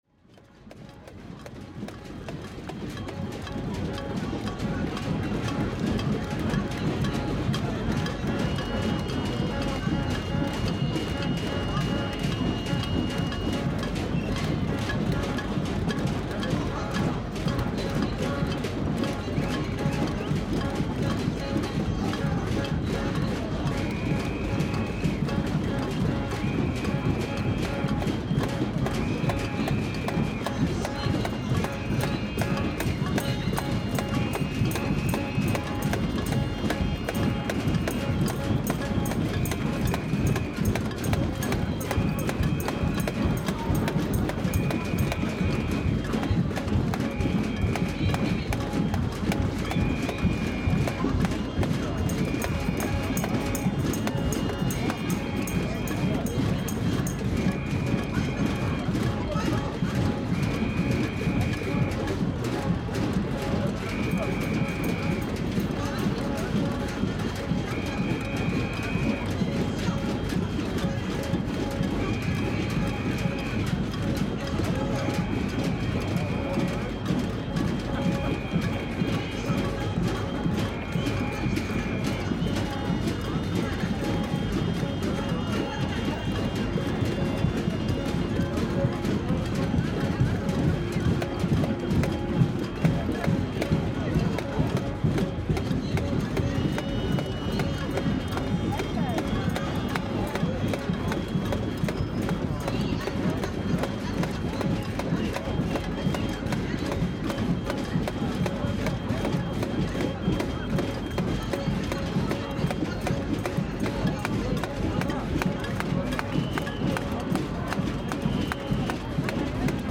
The following recording is recorded at the „first day in protest“ when around 20 thousand citizens gathered together downtown Reykjavik in the front of the Parliament house.